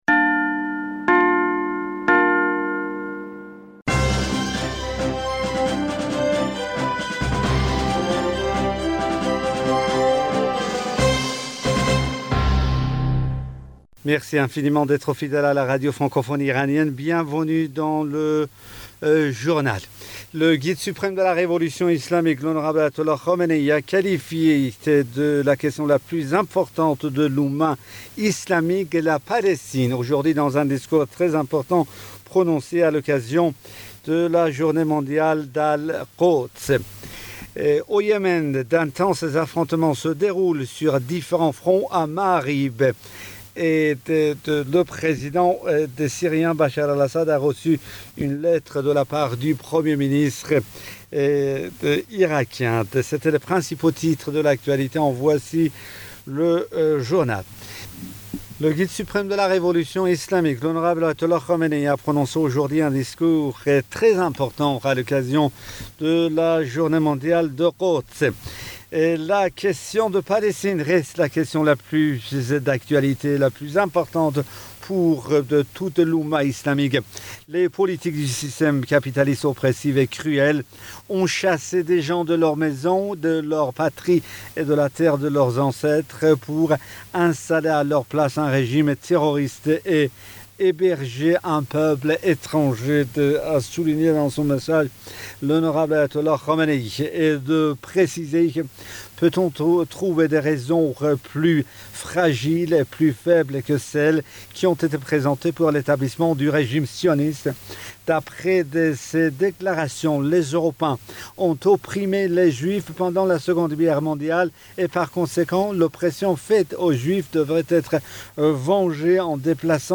Bulletin d'information du 07 mai 2021